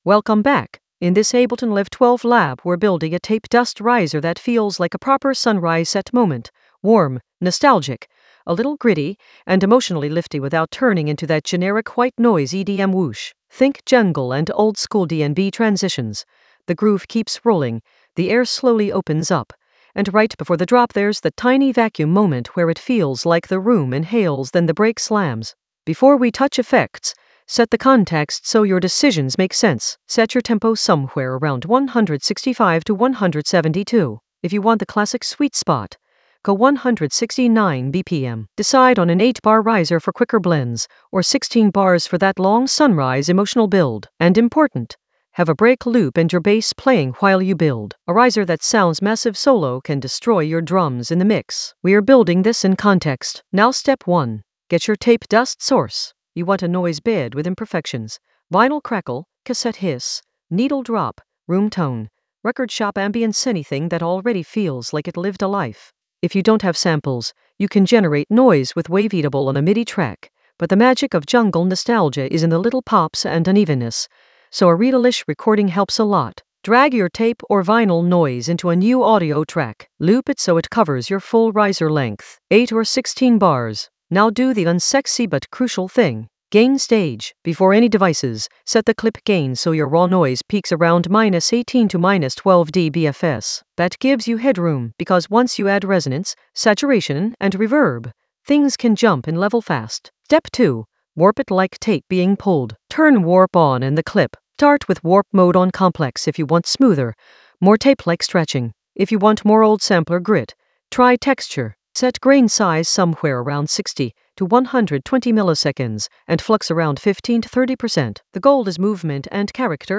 Narrated lesson audio
The voice track includes the tutorial plus extra teacher commentary.
An AI-generated intermediate Ableton lesson focused on Tape Dust riser warp lab for sunrise set emotion in Ableton Live 12 for jungle oldskool DnB vibes in the Atmospheres area of drum and bass production.